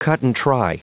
Prononciation du mot cut-and-try en anglais (fichier audio)
Prononciation du mot : cut-and-try